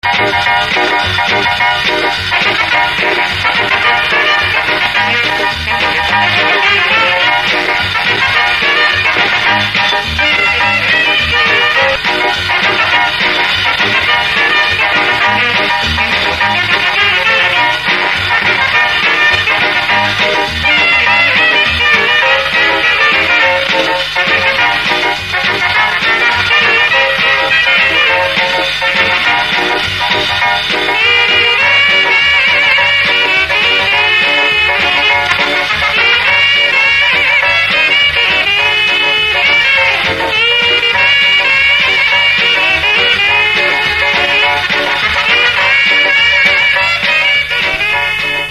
Первые четыре - в исполнении эстрадных оркестров.